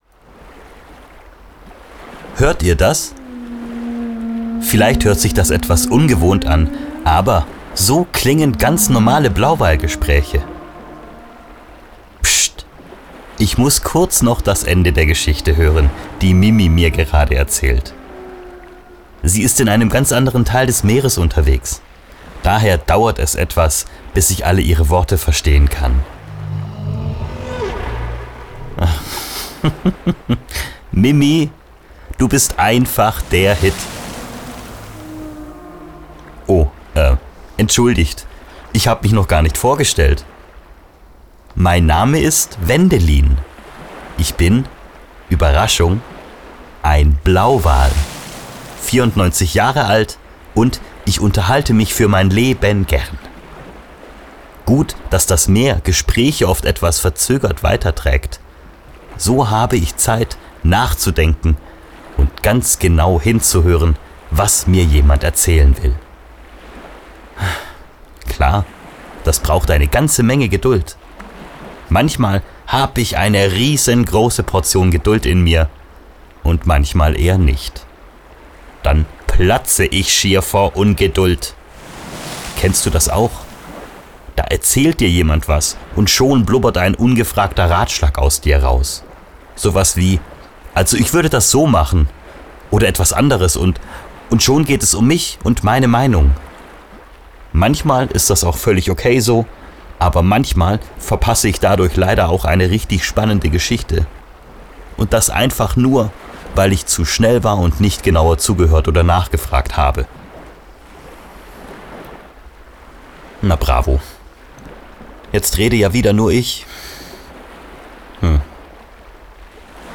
• Sounddesign und Effekte